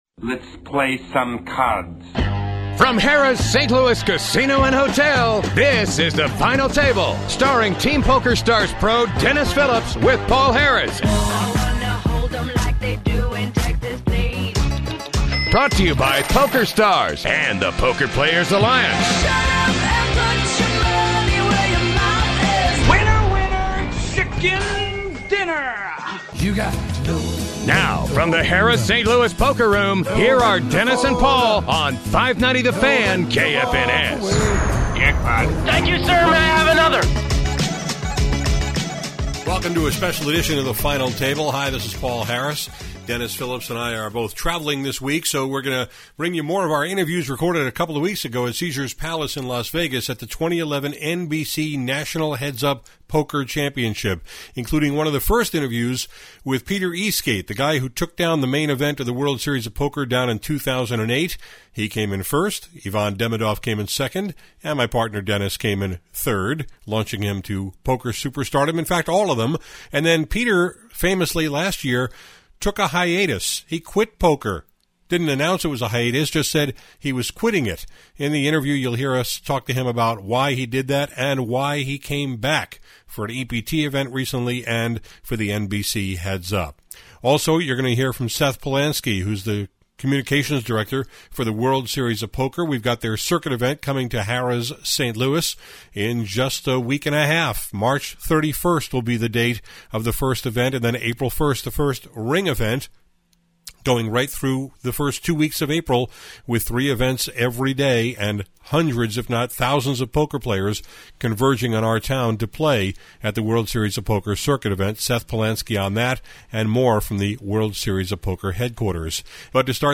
Today on The Final Table radio show, we have more interviews we recorded two weeks ago at the NBC National Heads-Up Poker Championship at Caesar’s Palace in Las Vegas.